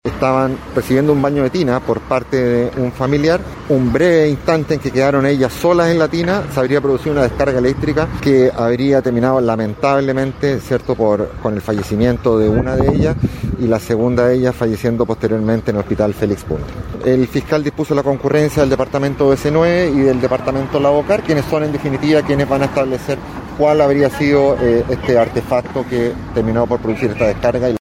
La situación está siendo investigada por el OS9 y el Laboratorio de Criminalística de Carabineros, institución que dio más detalles de lo ocurrido.
250-cuna-tina-carabineros.mp3